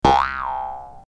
bounce1.ogg